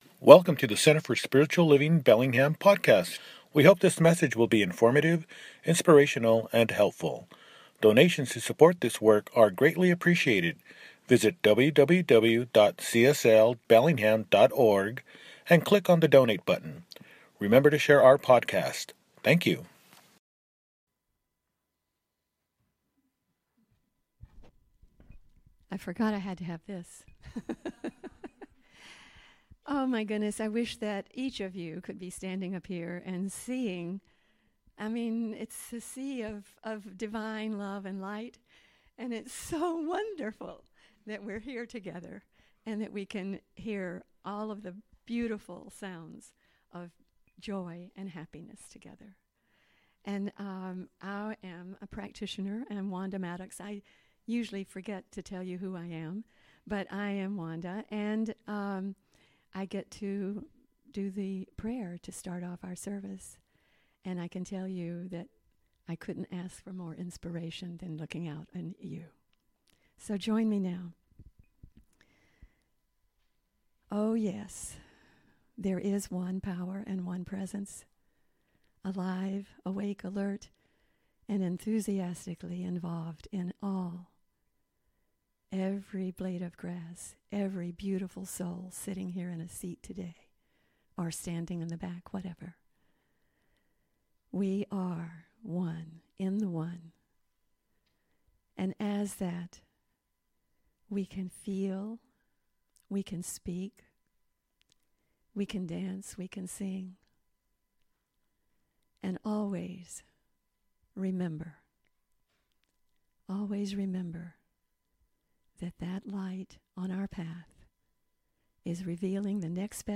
Getting Unhooked – Celebration Service